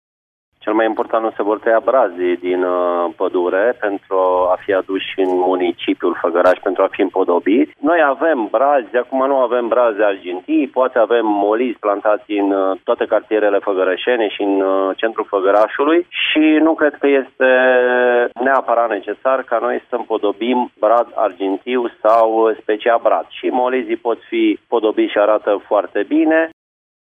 Primarul municipiului Făgăraş, Gheorghe Sucaciu:
PRIMAR-FĂGĂRAȘ-brazi.mp3